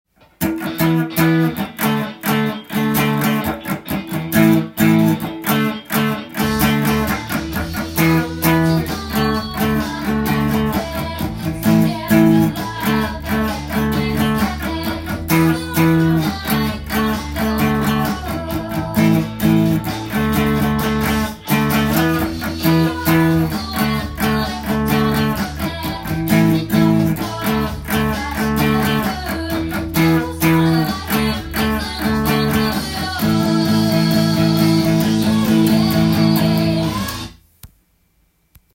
エレキギター用TAB譜
音源にあわせて譜面通り弾いてみました
全てパワーコードで時折ミュート音が出てきます。
ギターのリバーブ感が古っぽいサーフミュージック感満載です。